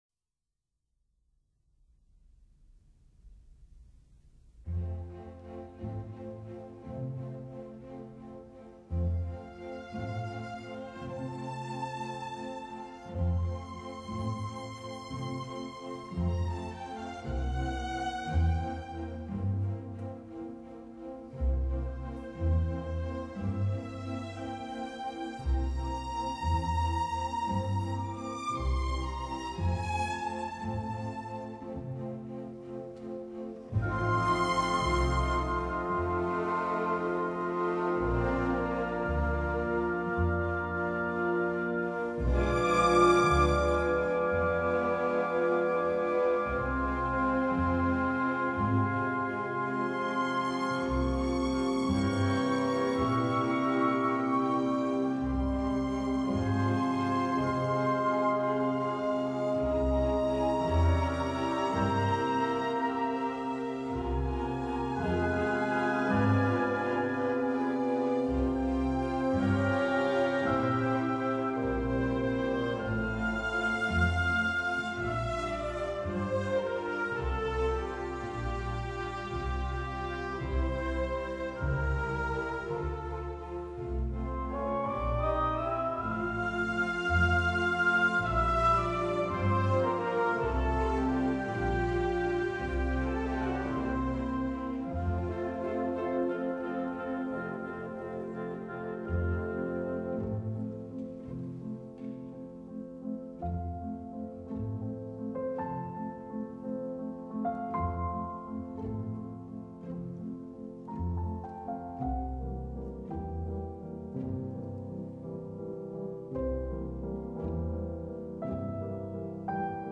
piano-concerto-21